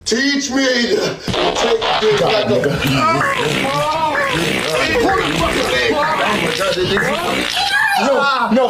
yes king teach me Meme Sound Effect
yes king teach me.mp3